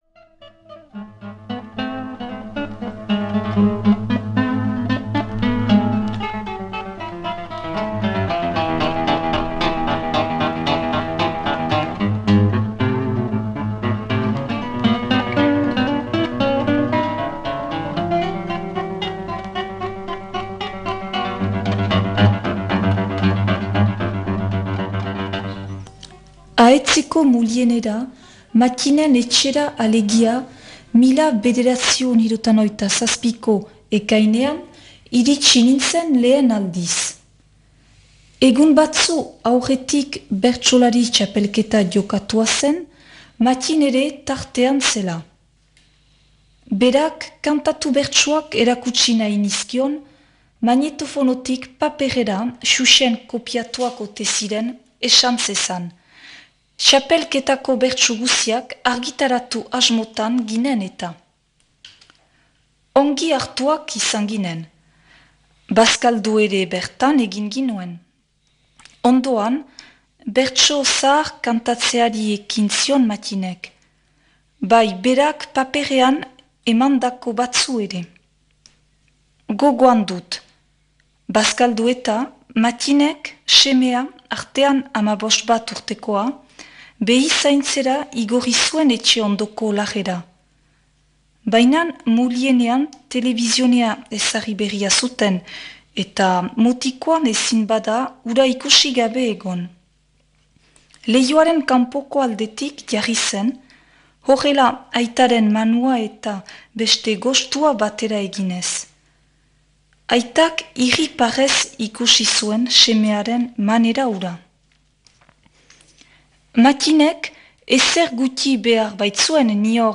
proposatu irakurketa da.